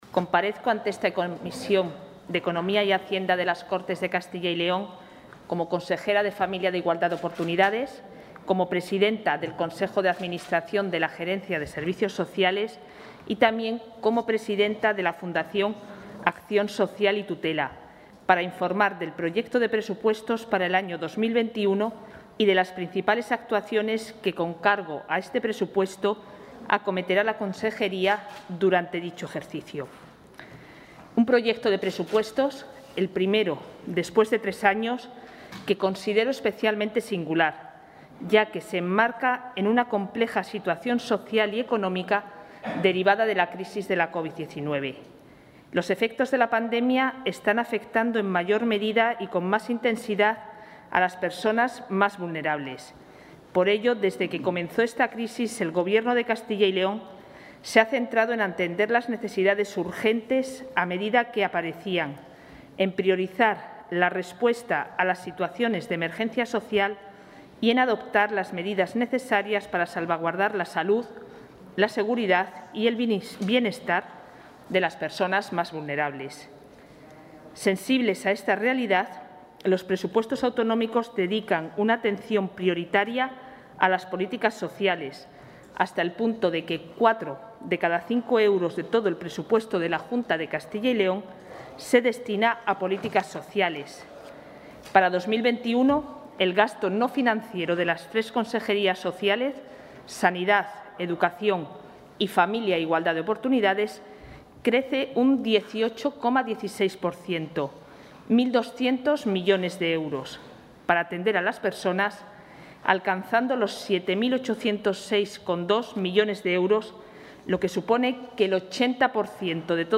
La consejera de Familia e Igualdad de Oportunidades, Isabel Blanco, ha presentado ante la Comisión de Economía y Hacienda de las Cortes...
Intervención de la consejera de Familia e Igualdad de Oportunidades.